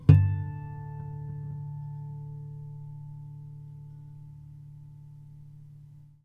harmonic-10.wav